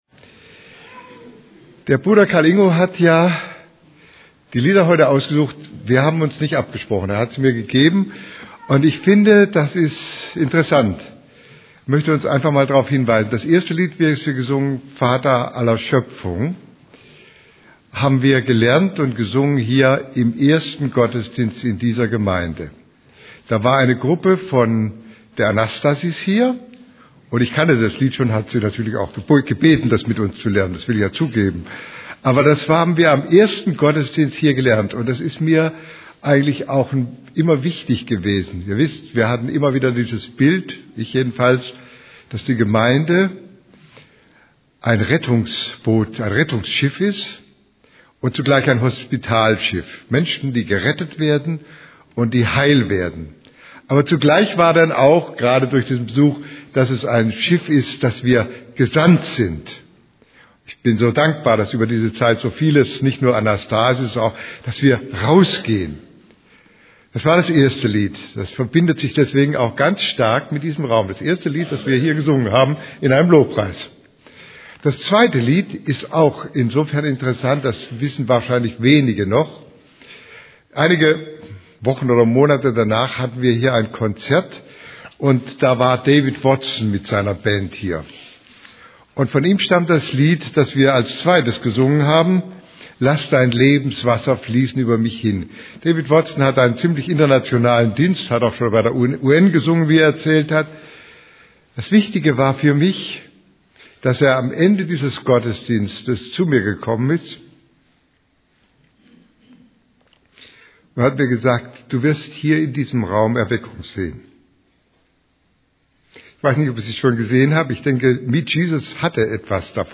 Predigt vom 23. Januar 2011 Predigt